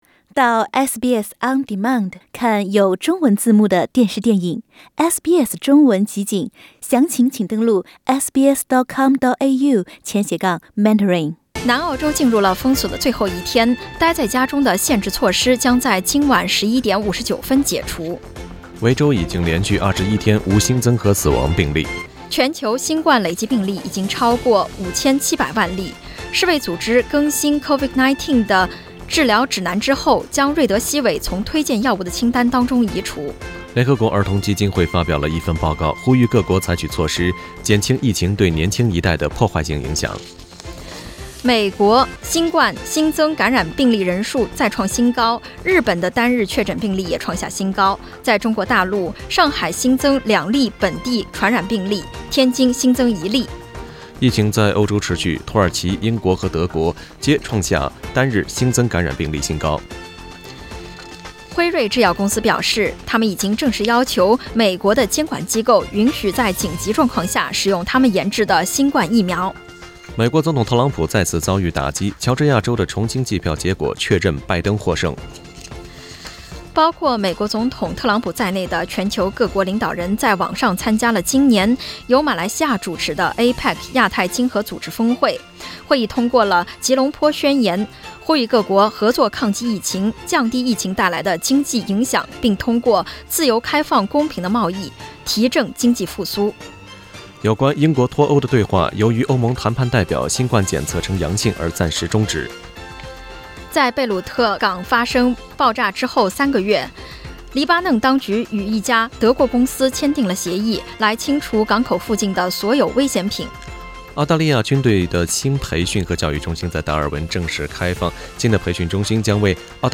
SBS早新闻（11月21日）